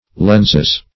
Lens \Lens\ (l[e^]nz), n.; pl. Lenses (-[e^]z). [L. lens a